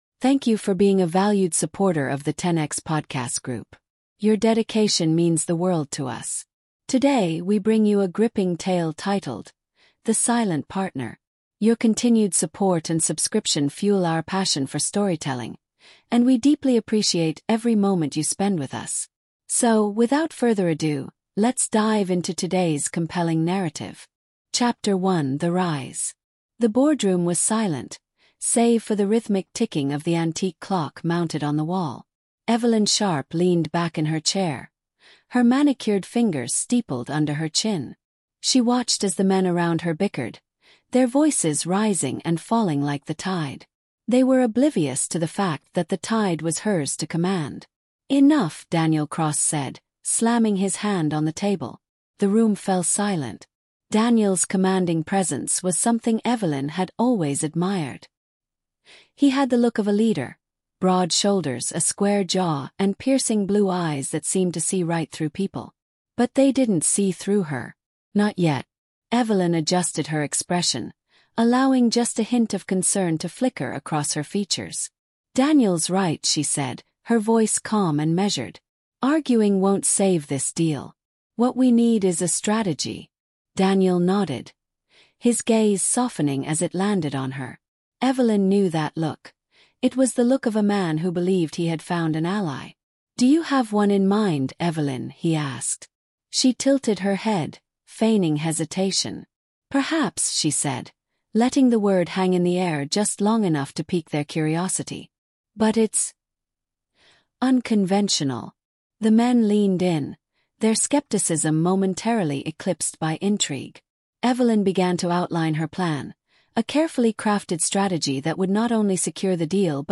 The Silent Partner is a thrilling storytelling podcast that takes listeners deep into the cutthroat world of corporate power, manipulation, and betrayal.